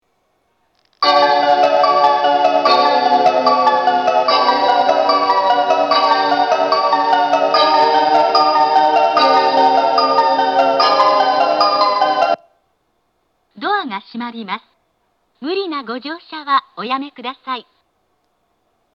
下り発車メロディー
0.7コーラスです。